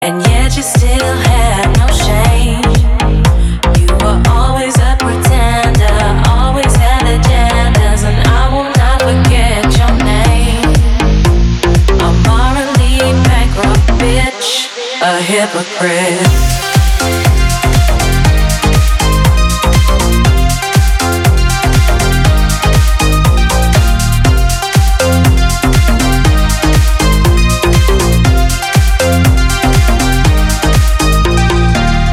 • Качество: 320, Stereo
ритмичные
громкие
женский вокал
deep house
басы
nu disco
Vocal House
Жанр: deep house, vocal house, nu disco, deep disco